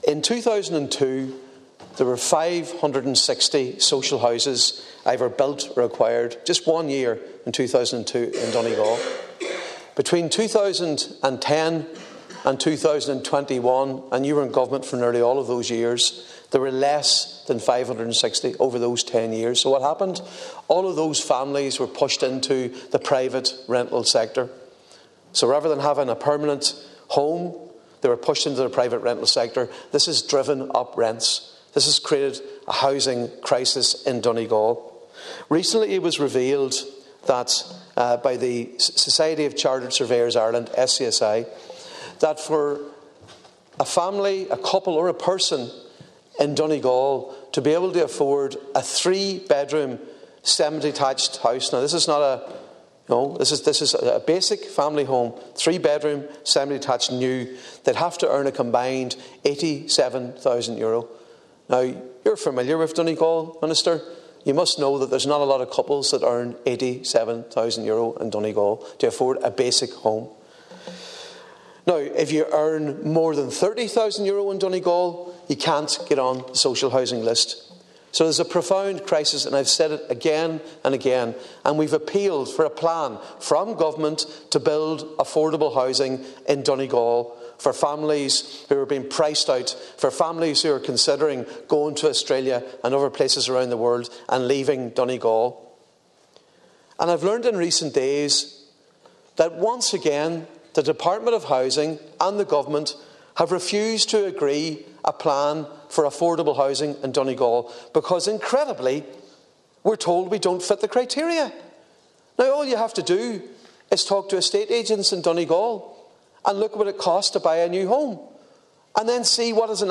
Speaking during the debate on a Labour Party housing debate, Deputy MacLochlainn claimed current government housing policy discriminates against Donegal, and that must be addressed…………….